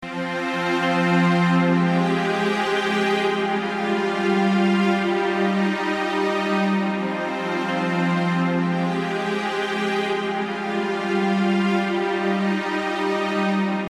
描述：字符串短语
Tag: 120 bpm Classical Loops Strings Loops 2.33 MB wav Key : Unknown